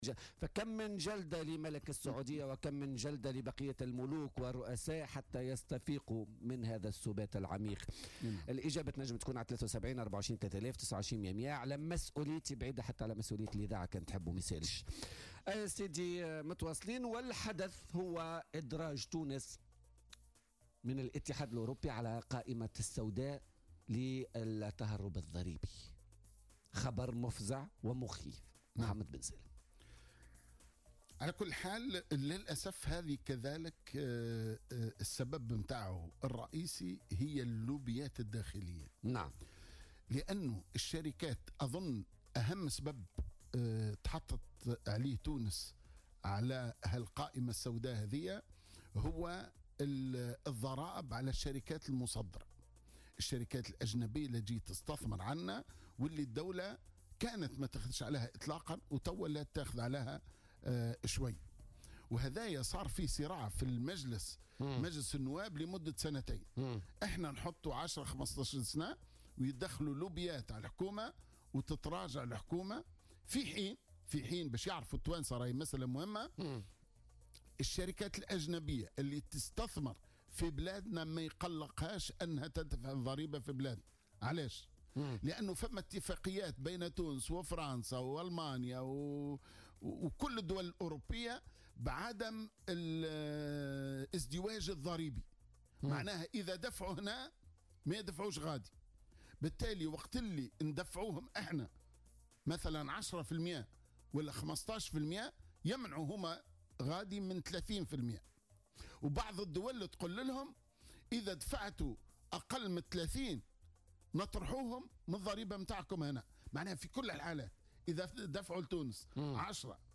أكد القيادي في حركة النهضة محمد بن سالم ضيف بوليتكا اليوم الأربعاء 6 ديسمبر 2017 أن أهم سبب لإدراج تونس ضمن قائمة سوداء وتصنيفها كملاذ ضريبي هي اللوبيات الداخلية والضرائب على الشركات المصدرة.